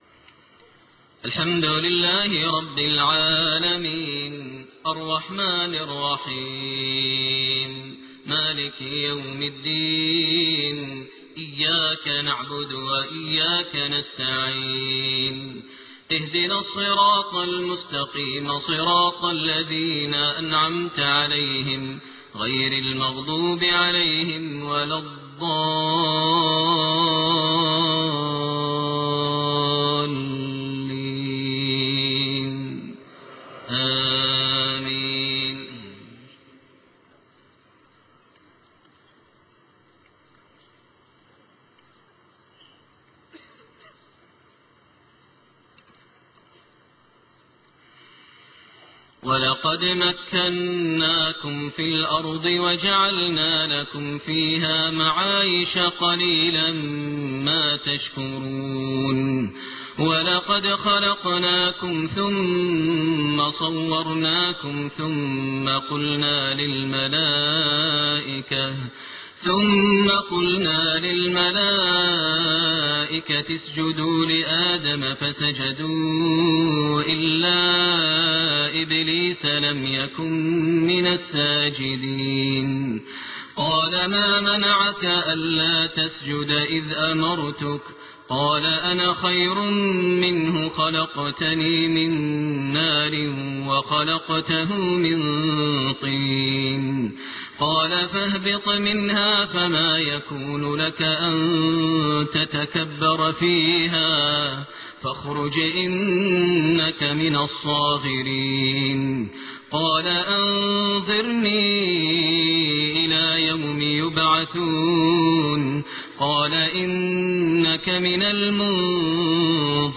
Fajr prayer from Surah Al-A'raaf > 1429 H > Prayers - Maher Almuaiqly Recitations